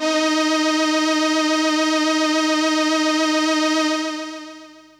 55bd-syn12-d#4.aif